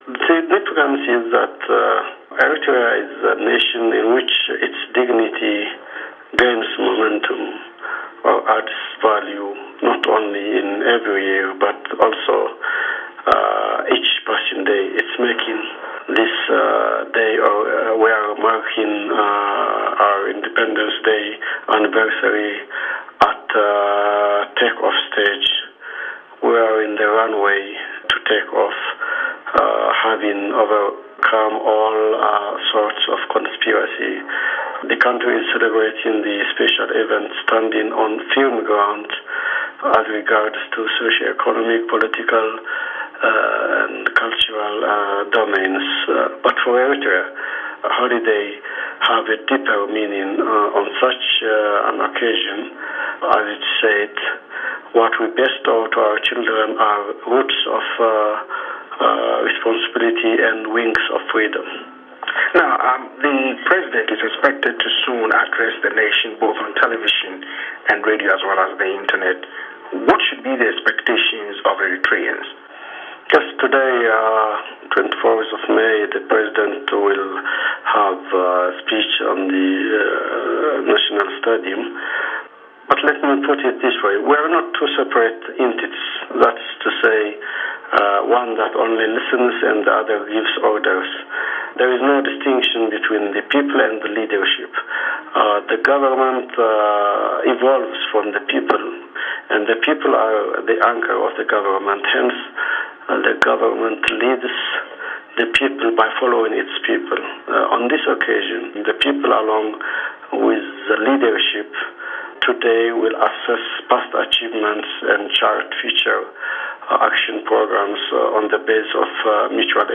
Interview with Eritrean Minister of Information